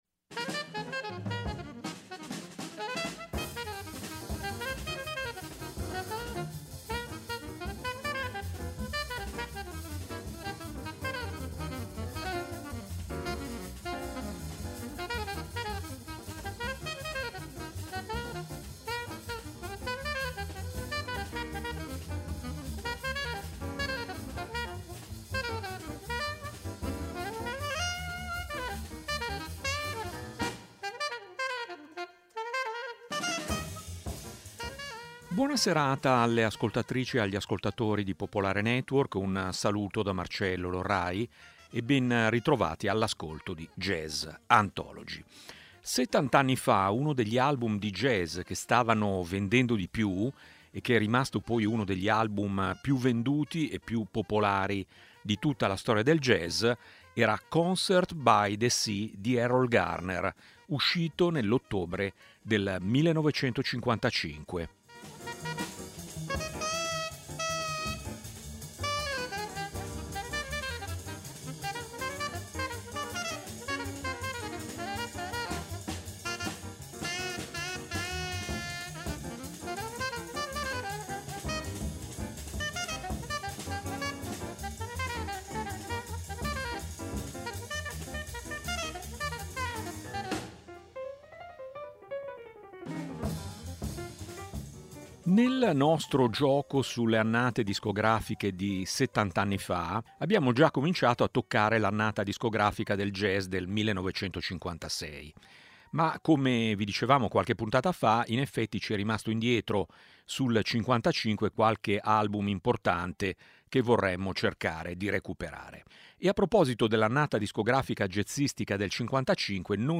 jazz
il pianista afroamericano